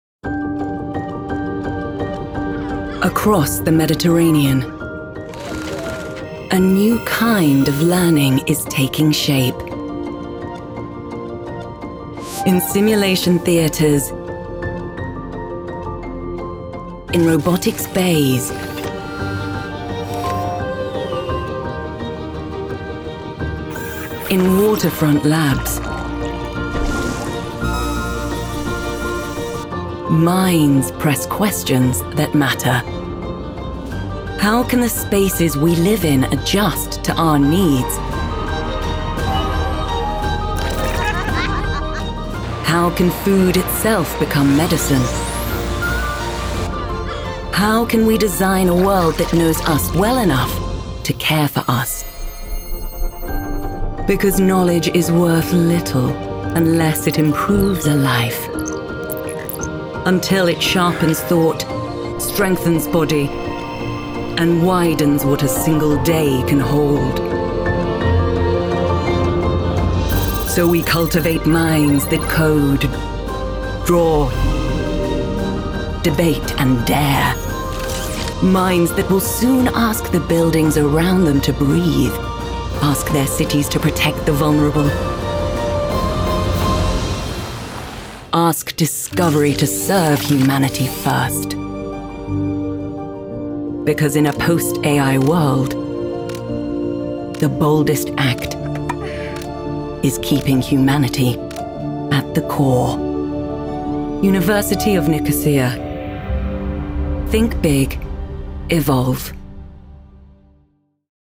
Cinematic and inspiring - University of Nicosia